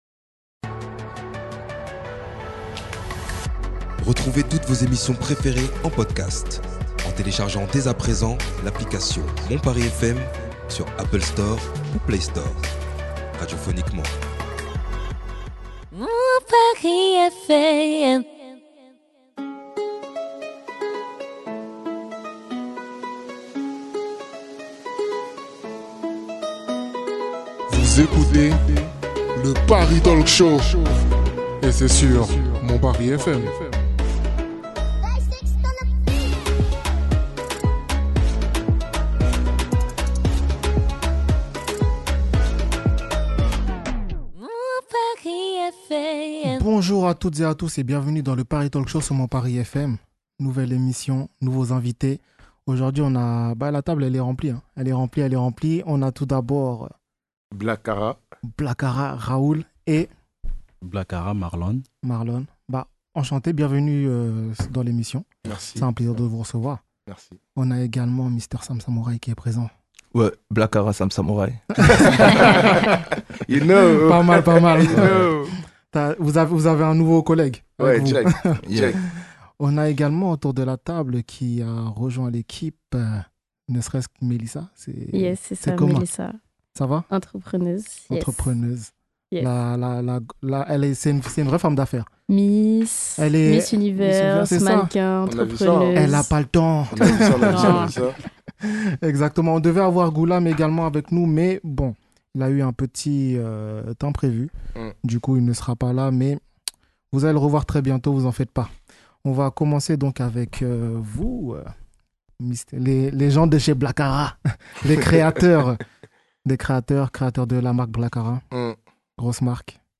15 avril 2025 - 19:00 Écouter le podcast Télécharger le podcast Au programme dans le "Paris Talk Show" nous recevons 2 invités.
Une émission haut en couleur qui se terminera avec une touche musicale